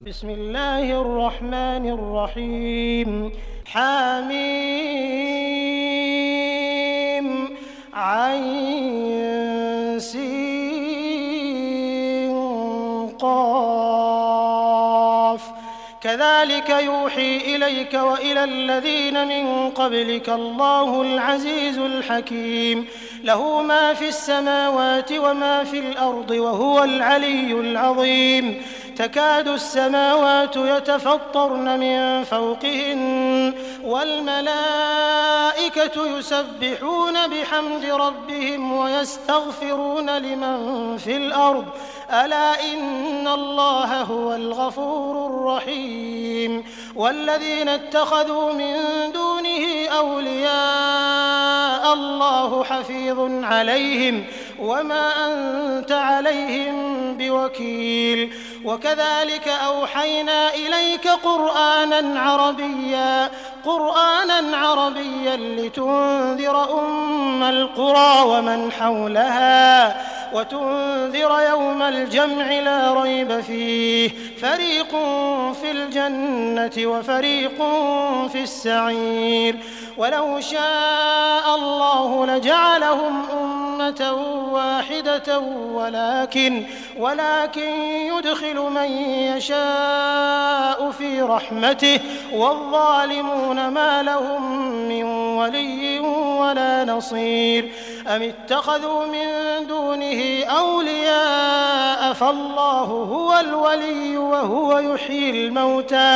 Surah Ash Shura Beautifull Recitation MP3 Download By Abdur Rahman Al Sudais in best audio quality.
Sheik al-Sudais has an incredibly resonant and enchanting voice which has been refined of animating the Muslim people group, while his motivating recitations of the Quran have an intelligent impact on the hearts of the audience members, passing on them to another district.